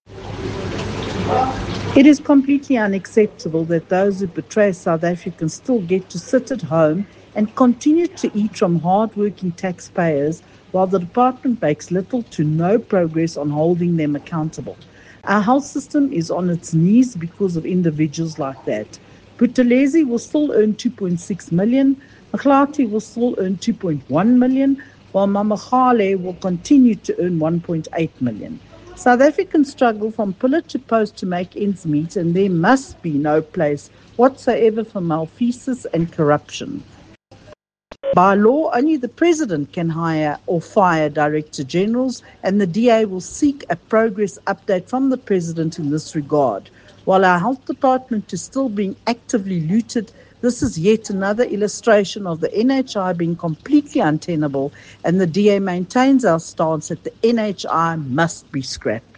Issued by Michéle Clarke MP – DA Spokesperson on Health
Soundbite by Michéle Clarke MP.